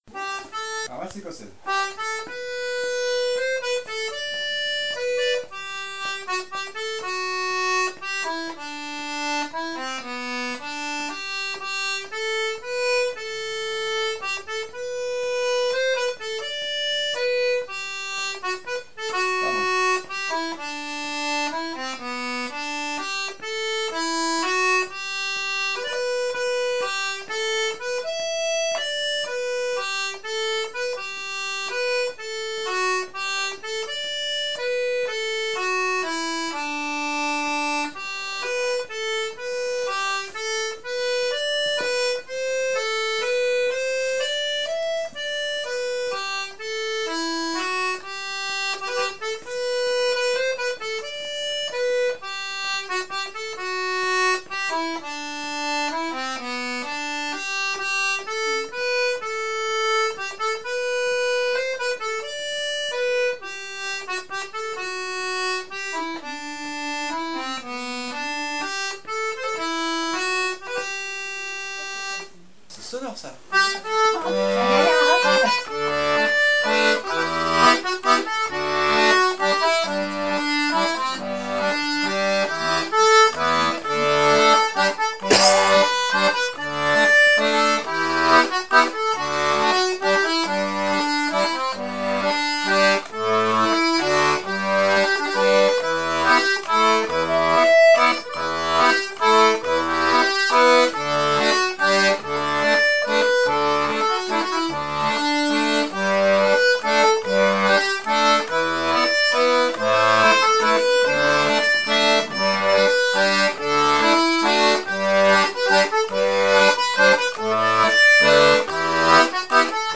l'atelier d'accordéon diatonique
Jouer tranquillement (noire = 100)
A la main gauche jouer la Basse puis les 2 accords liés